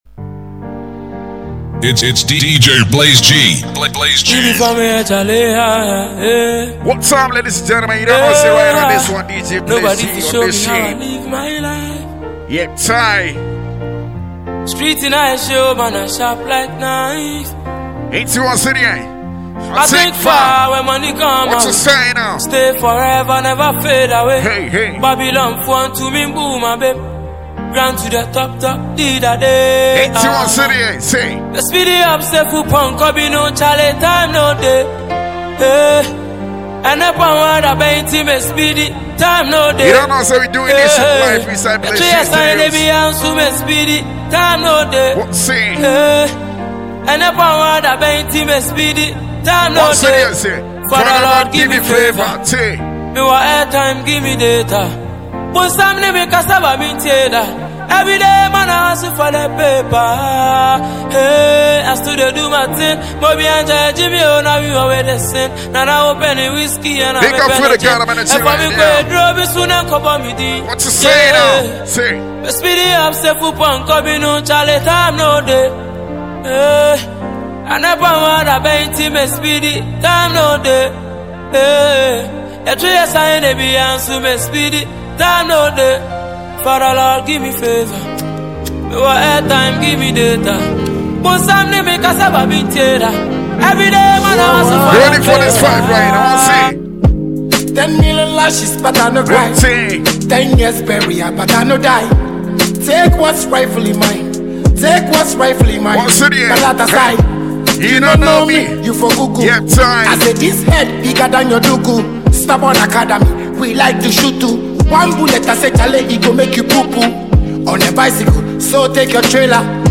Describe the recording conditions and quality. " a live mix that is available for free mp3 download.